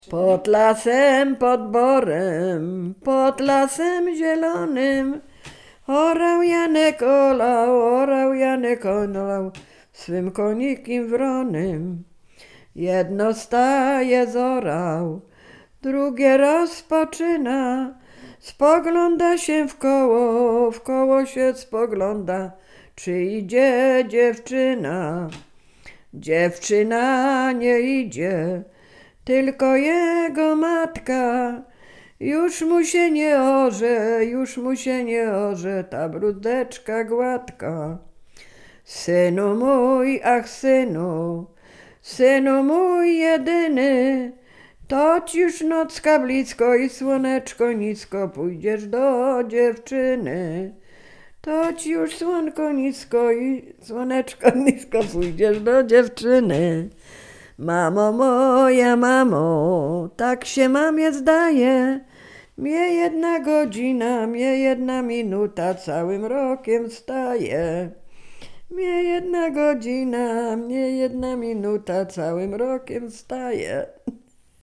Łódzkie, powiat sieradzki, gmina Brzeźnio, wieś Kliczków Mały
Liryczna
liryczne miłosne